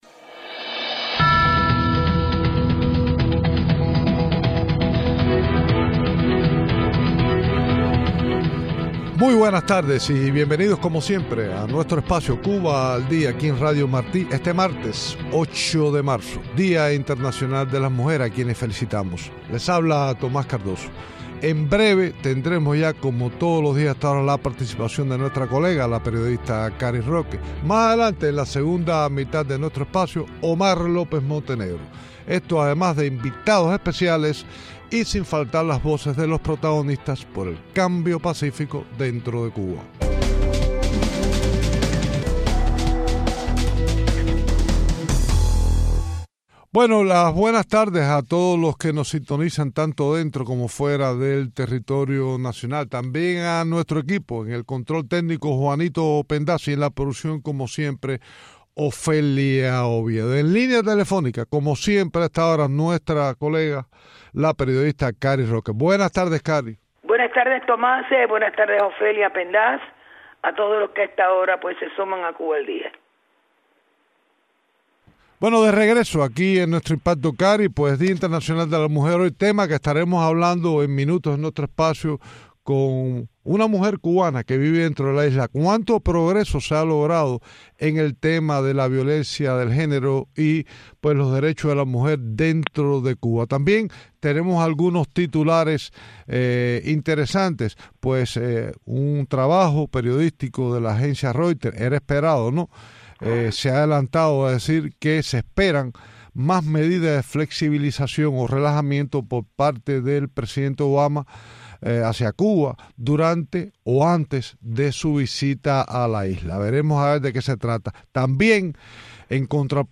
Emtrevistas